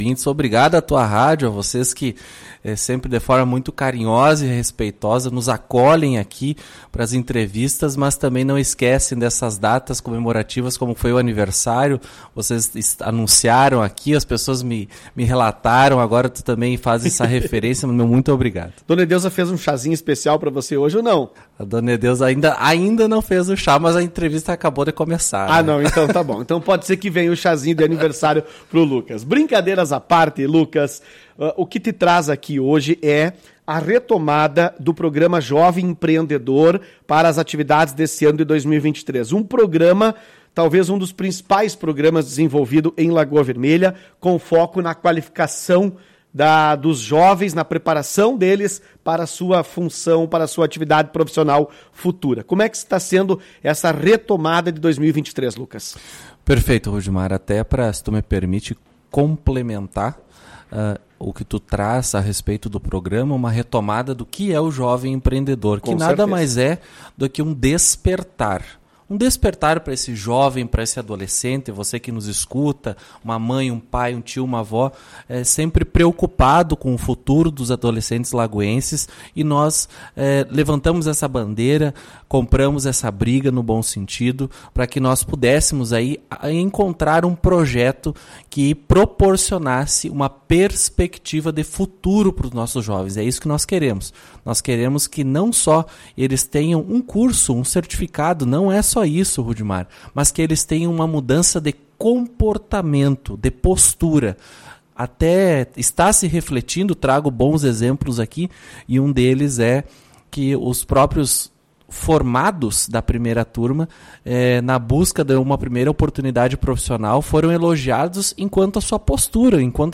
O secretário de Desenvolvimento de Lagoa Vermelha, Lucas Motta explica como será a dinâmica do projeto neste ano. Ouça a entrevista.